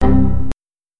Error4.wav